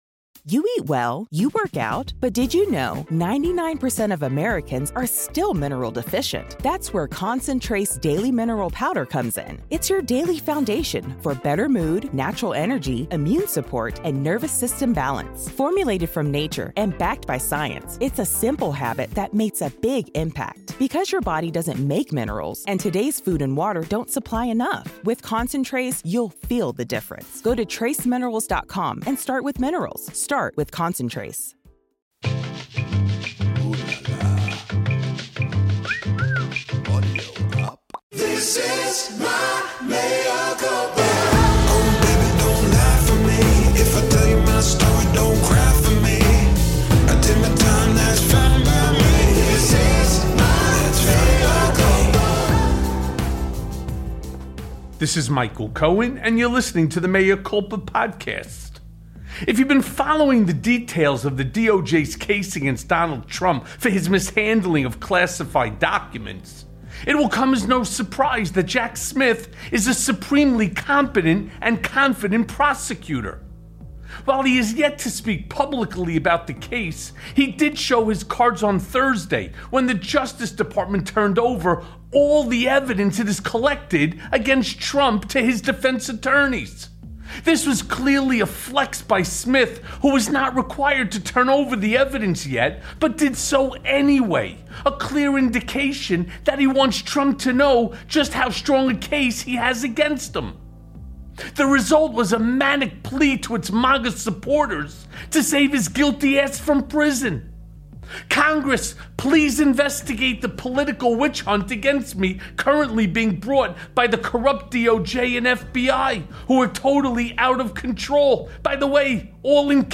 Breaking!! Overwhelming Evidence Has Trump Scared Sh!tless + A Conversation with David Corn
Mea Culpa welcomes David Corn back to the show, legendary newsman David Corn.